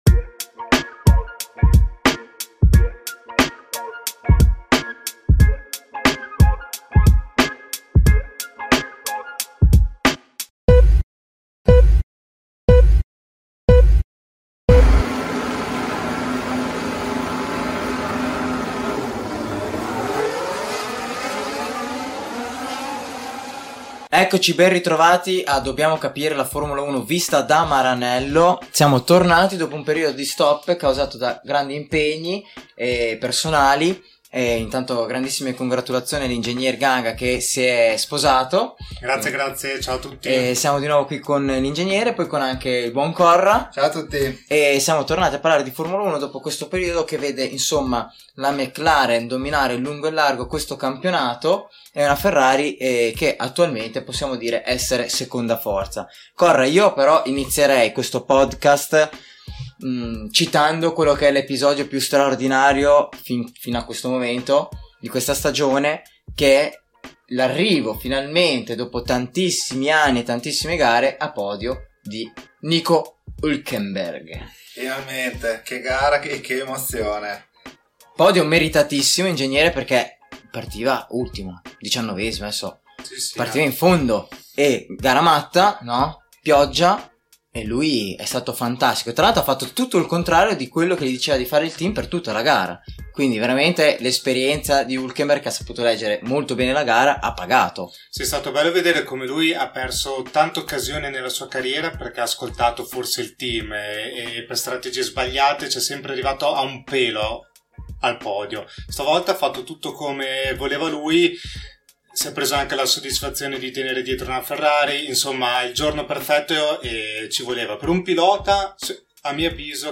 tre amici che da Maranello chiacchierano di Formula 1 alla fine di ogni Gran Premio.